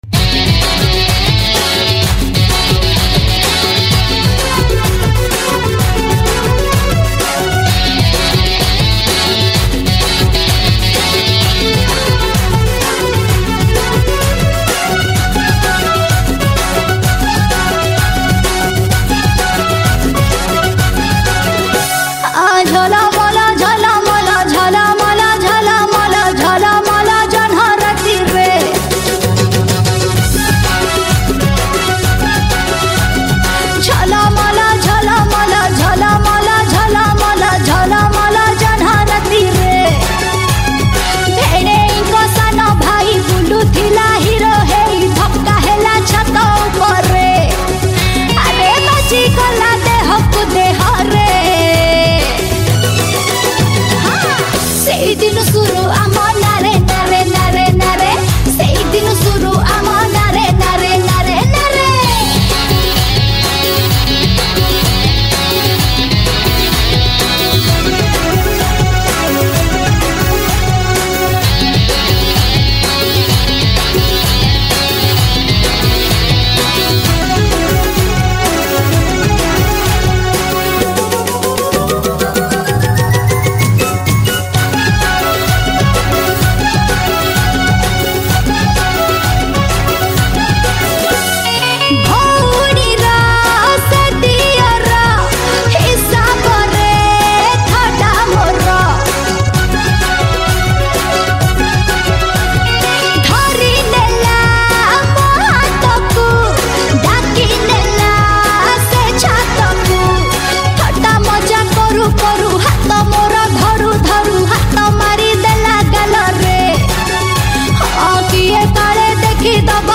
Melodious